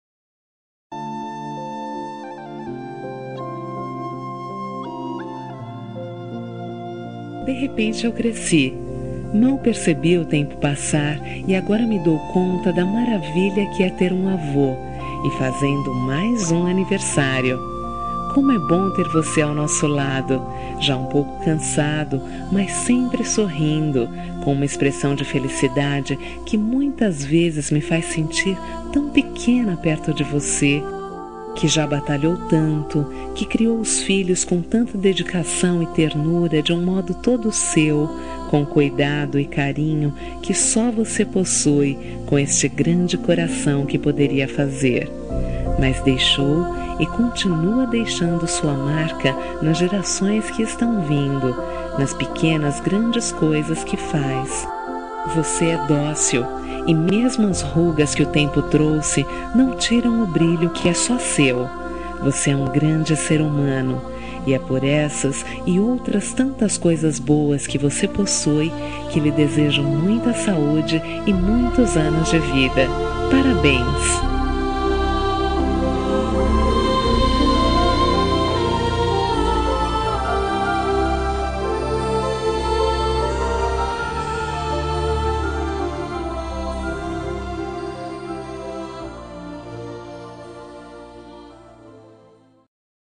Aniversário de Avô – Voz Feminina – Cód: 2086